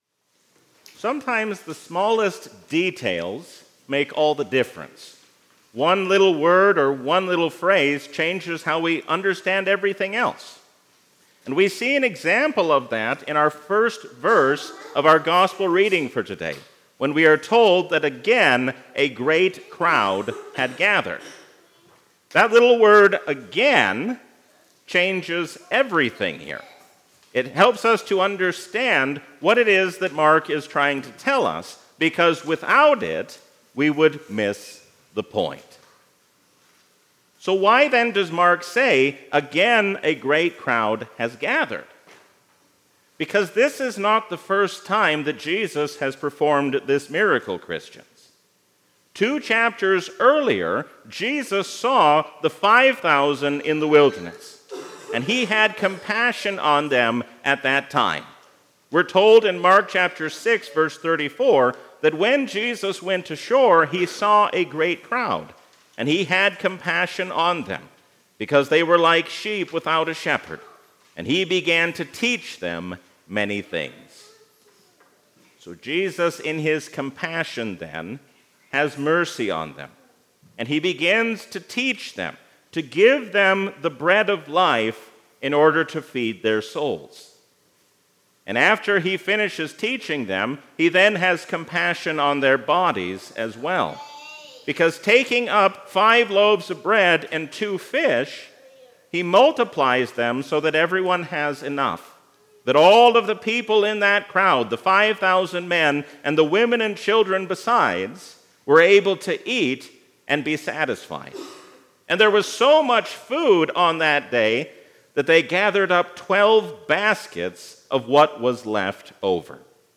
A sermon from the season "Trinity 2023."